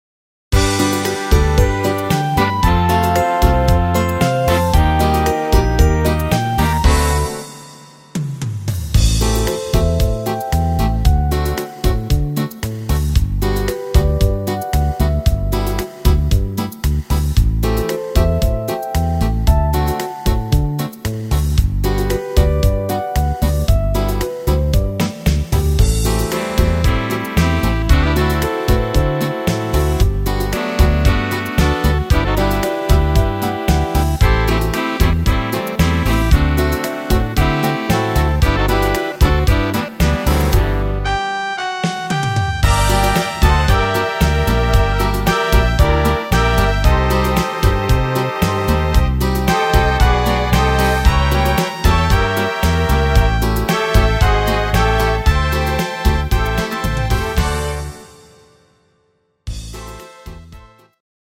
Instrumental Combo